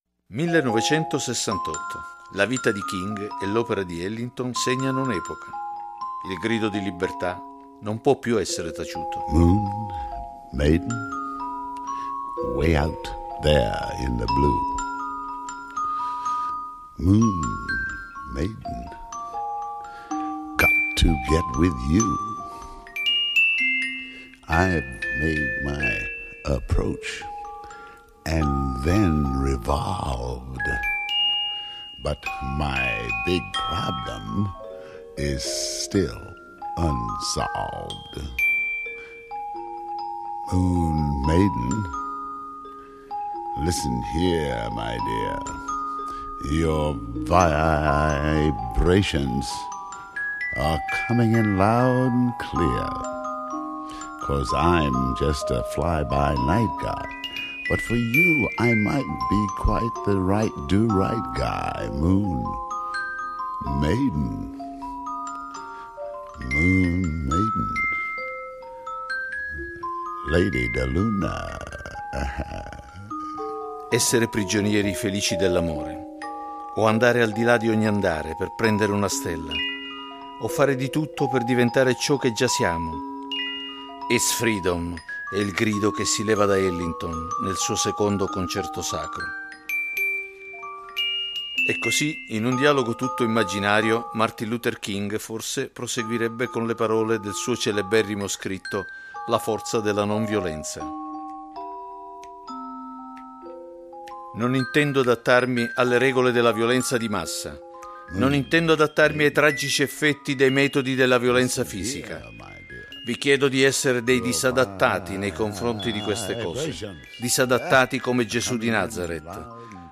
Il materiale musicale è per lo più del tutto inedito e grande enfasi viene data ai testi e agli interventi solisti sia dei musicisti che dei cantanti.